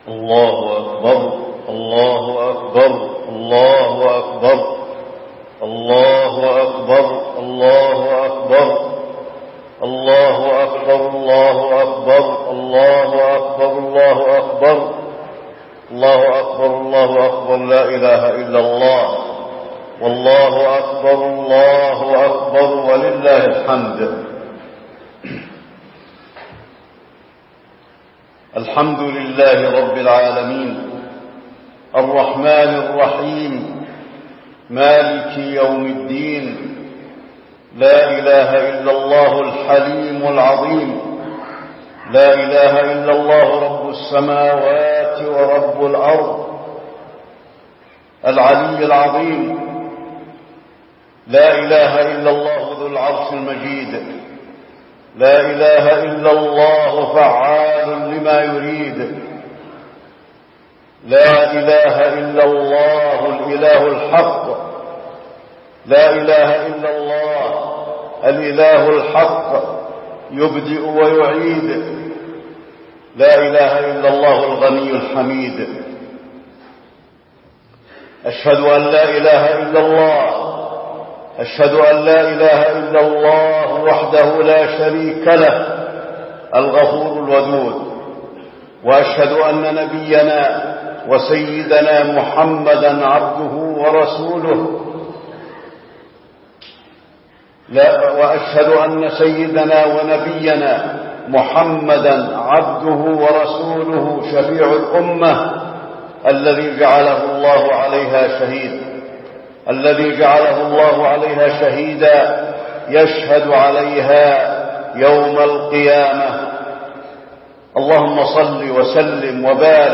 خطبة الاستسقاء - المدينة- الشيخ علي الحذيفي
تاريخ النشر ٢٤ محرم ١٤٣٦ هـ المكان: المسجد النبوي الشيخ: فضيلة الشيخ د. علي بن عبدالرحمن الحذيفي فضيلة الشيخ د. علي بن عبدالرحمن الحذيفي خطبة الاستسقاء - المدينة- الشيخ علي الحذيفي